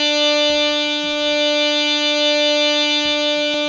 52-key13-harm-d4.wav